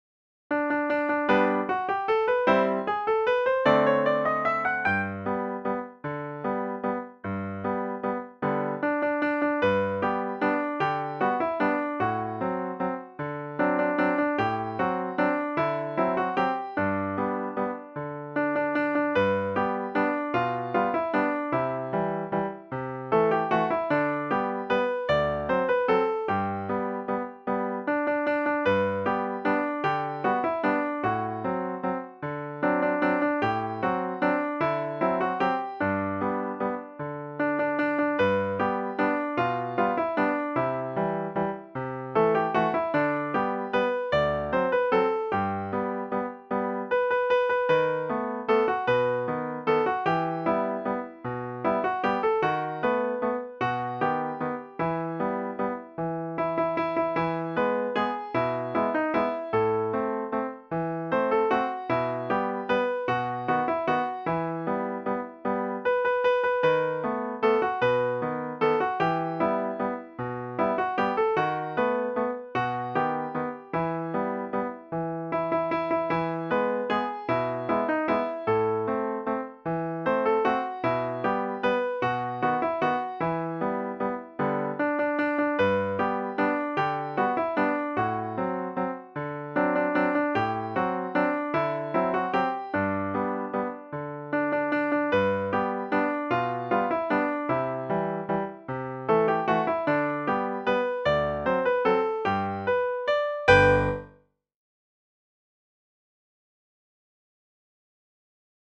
Free Piano Music!